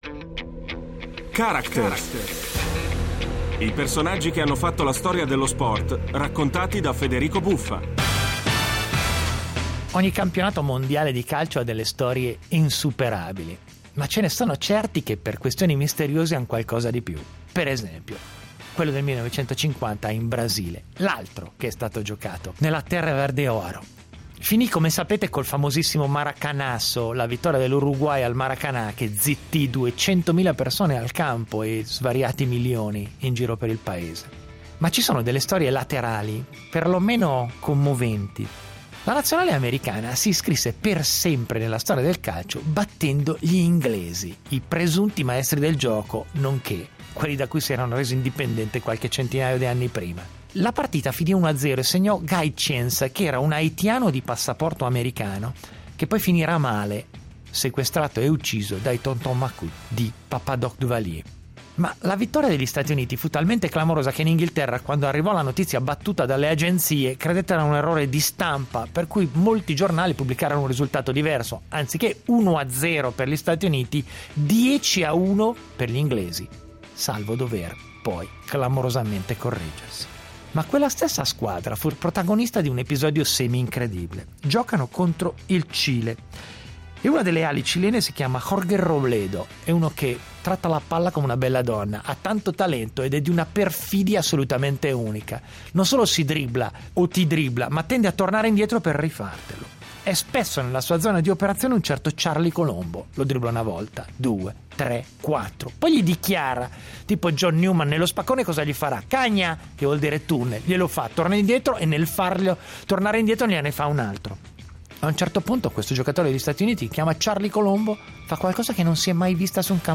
Con Federico Buffa
Cile-Stati Uniti ai mondiali brasiliani del 1950, terminata 5-2 in favore dei sudamericani, raccontata da Federico Buffa.